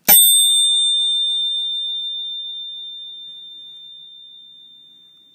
timer-done.wav